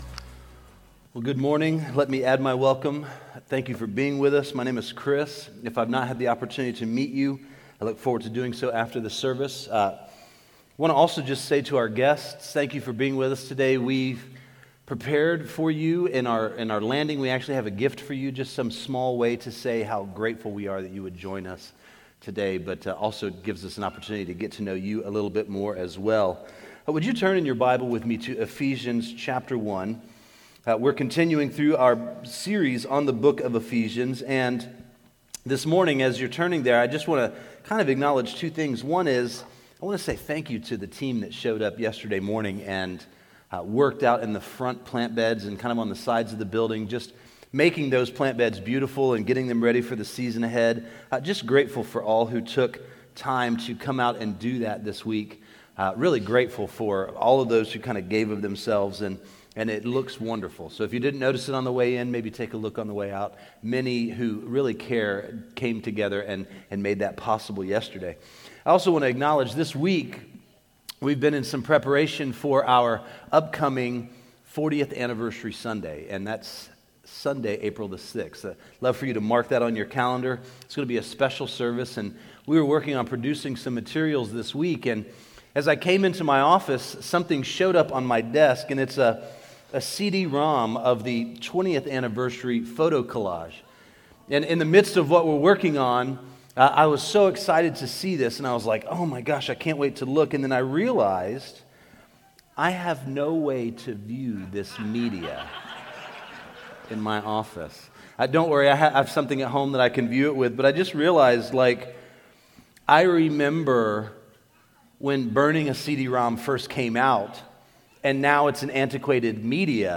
Metro Life Church Sermons
This page contains the sermons and teachings of Metro Life Church Casselberry Florida